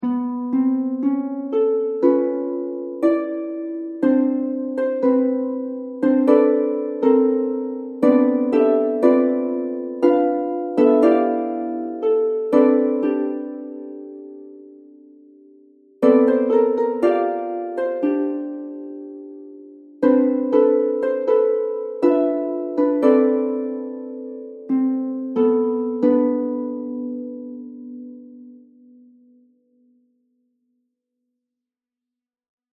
Jouer simultanément toutes les notes de chaque mot peut par exemple donner la partition dissonante qui suit.]
Suite d'accords dissonants donnés par chacun des mots ci-dessus